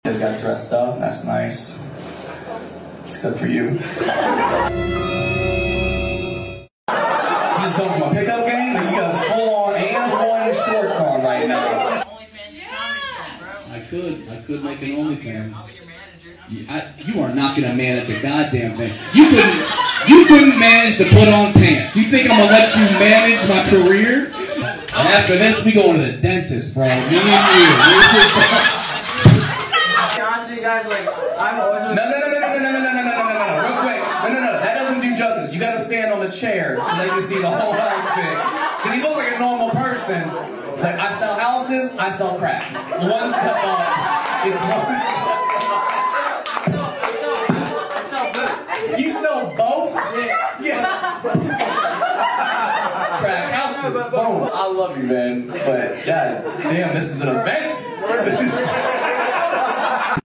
Matt Rife vs. Drunk Audience sound effects free download
You Just Search Sound Effects And Download. tiktok hahaha sound effect Download Sound Effect Home